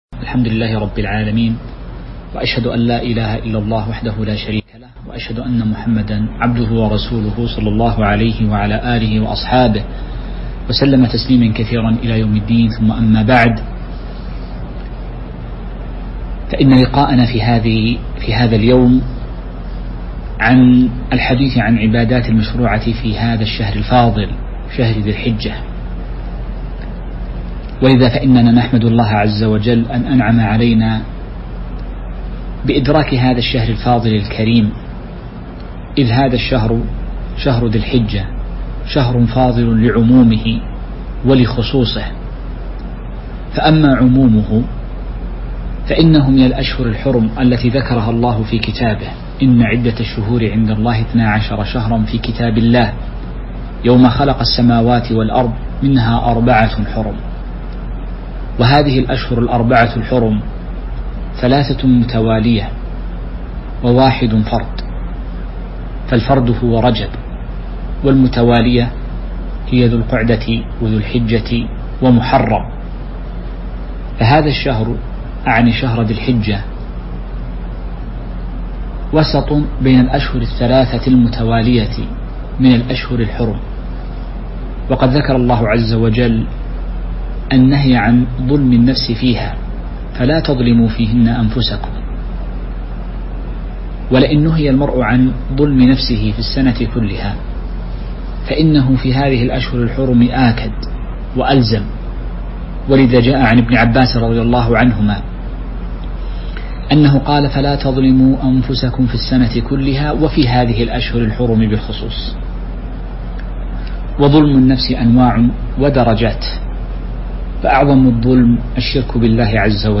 تاريخ النشر ٦ ذو الحجة ١٤٤١ هـ المكان: المسجد النبوي الشيخ